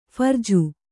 ♪ pharju